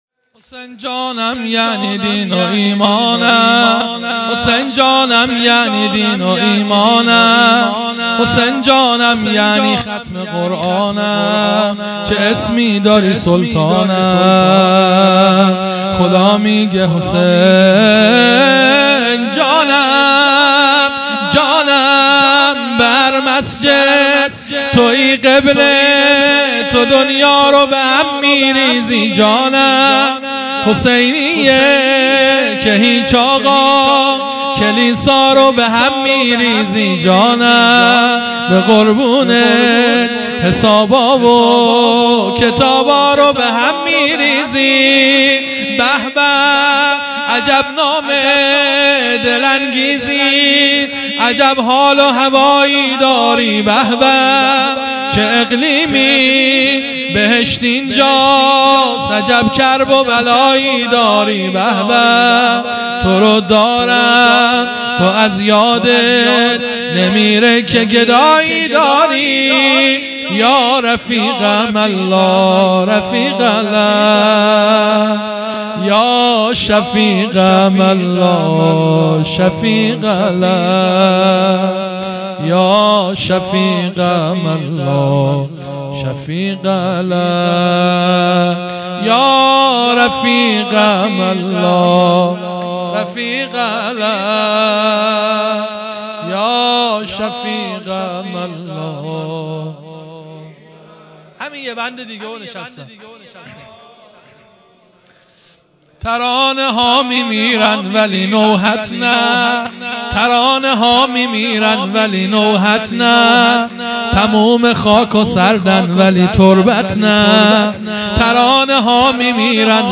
هیئت مکتب الزهرا(س)دارالعباده یزد
0 0 شور | حسین جانم یعنی دین و ایمانم مداح